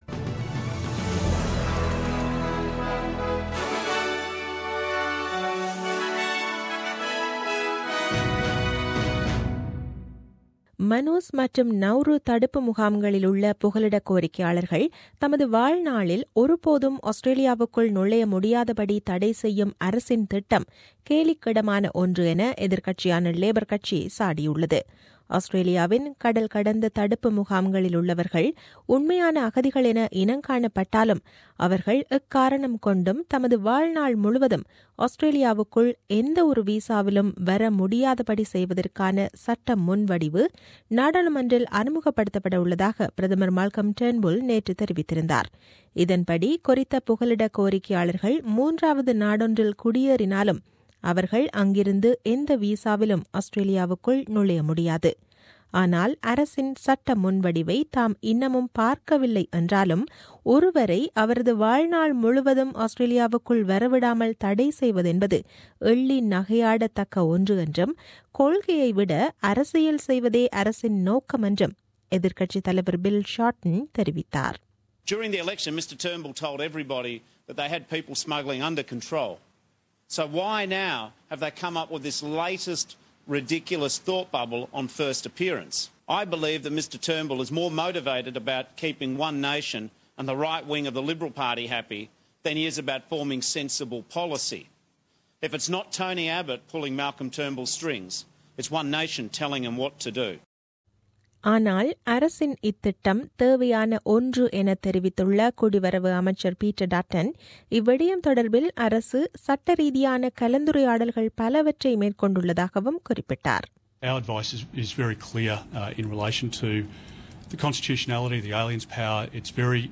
The news bulletin aired on 31 Oct 2016 at 8pm.